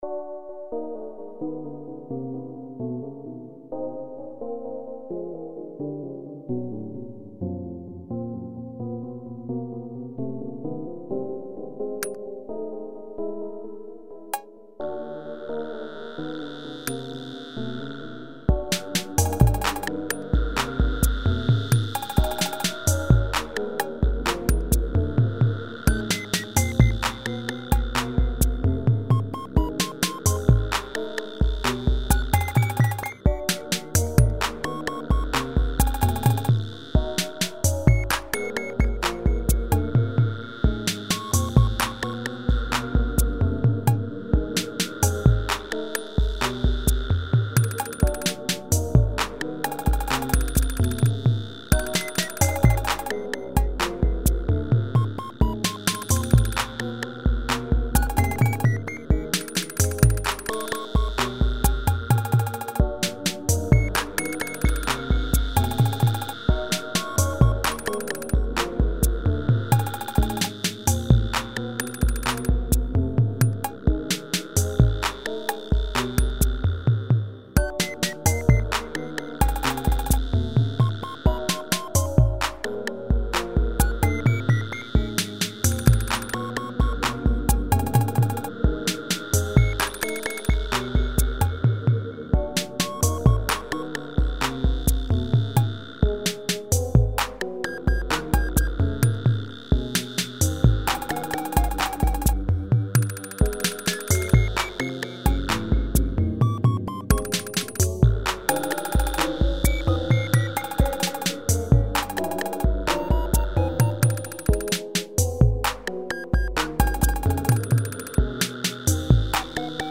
Categorised in: atmospheric, rhythmic